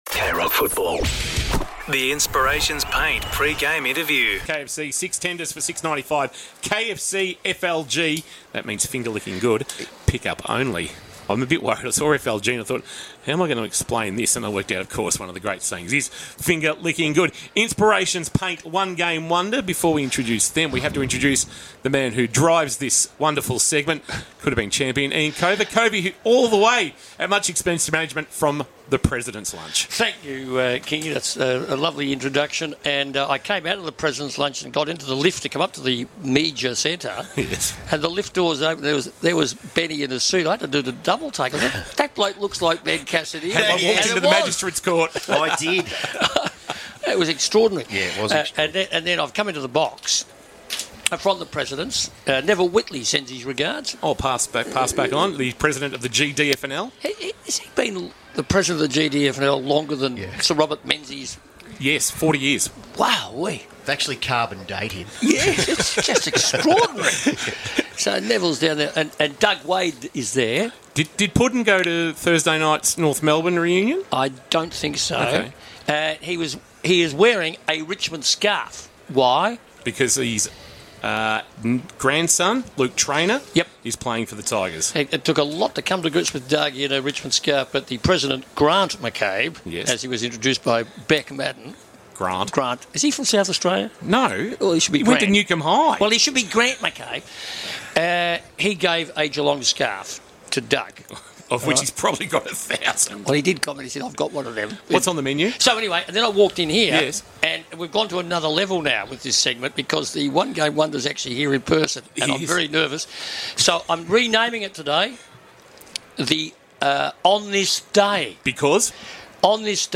2025 - AFL - Round 17 - Geelong vs. Richmond: Pre-match interview